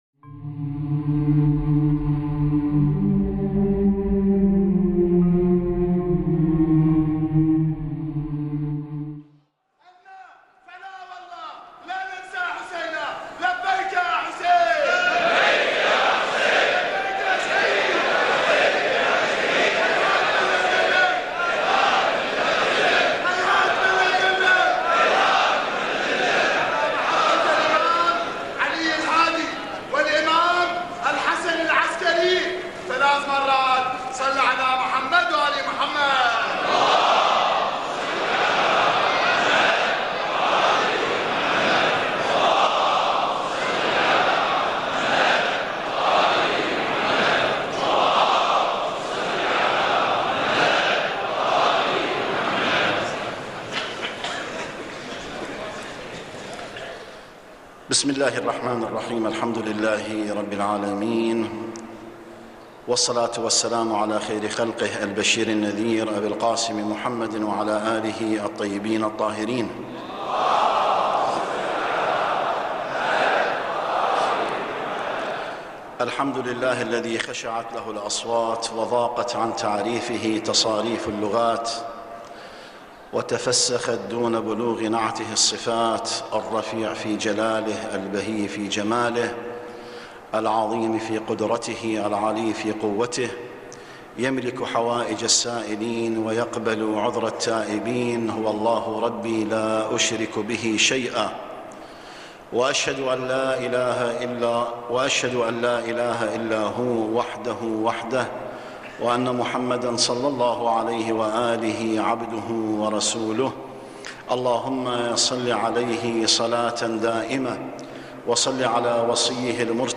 تناول ممثل المرجعية الدينية العليا السيد الصافي في خطبته الثانية من صلاة الجمعة 6 ربيع الاول 1434 هـ الموافق 18 كانون الثاني 2013م من الحرم الحسيني المطهر، ثلاثة أمور، كان أولها ما يتعلق بالوضع السياسي في البلاد وفي الثاني تناول ما يتعرض له البلد من ارهاب، فيما ختم خطبته بتناول ثقافة محبة الأوطان وتأثيرها على فعل ابنائها تجاهها.